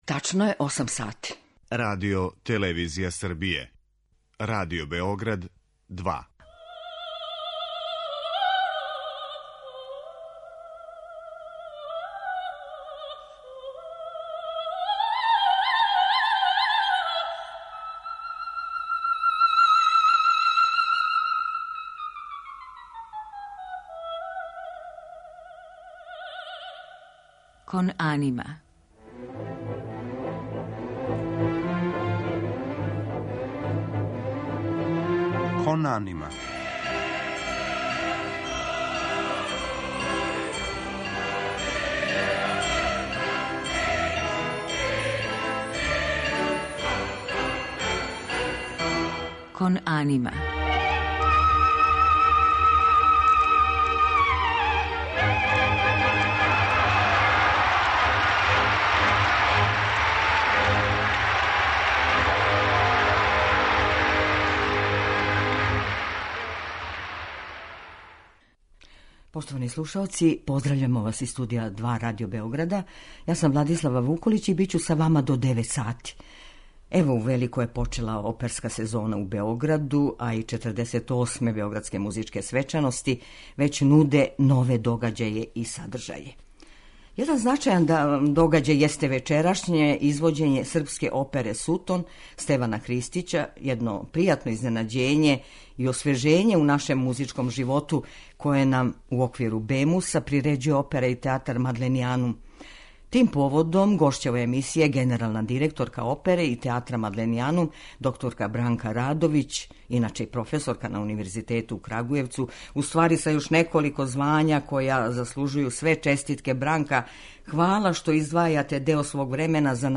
У музичком делу биће емитовани фрагменти балетског дивертисмана из опере "Сутон".